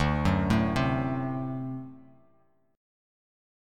D7 Chord
Listen to D7 strummed